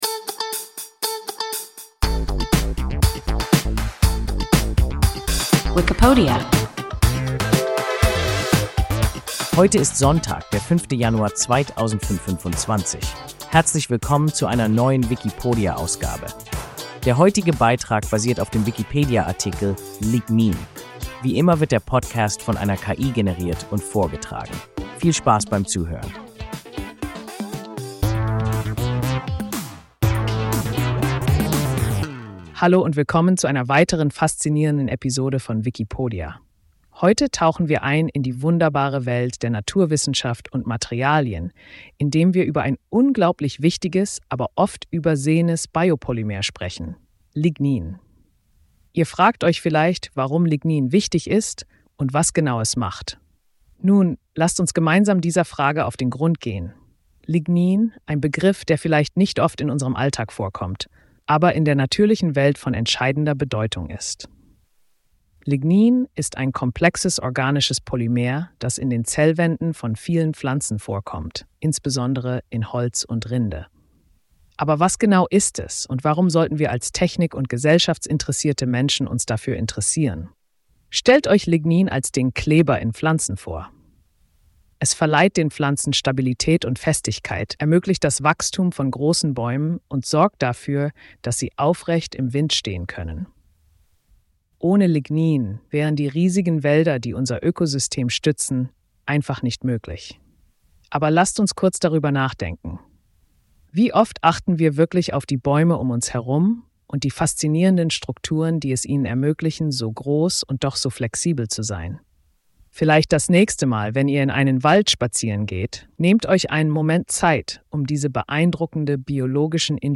Lignin – WIKIPODIA – ein KI Podcast